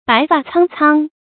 白發蒼蒼 注音： ㄅㄞˊ ㄈㄚˋ ㄘㄤ ㄘㄤ 讀音讀法： 意思解釋： 蒼蒼：灰白色。